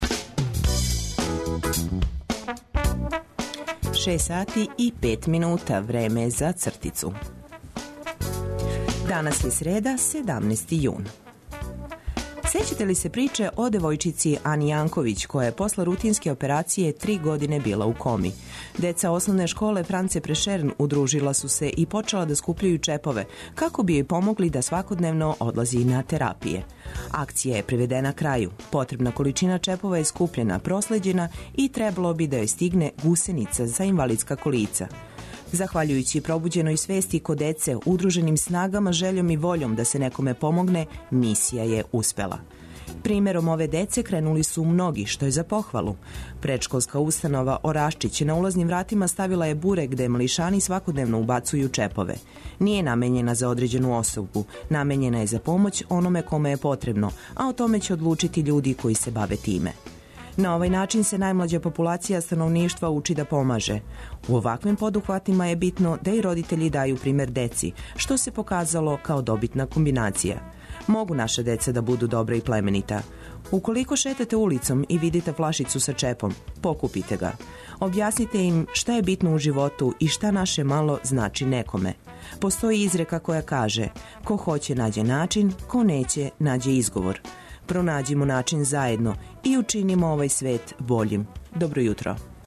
Док нас сунце лагано буди, ми смо ту да Вас информишемо о свим дешавањима битним за Вас. Добро познати прилози, сервисне, културне и спортске информације, прошаране добром музиком, обележиће још један Устанак.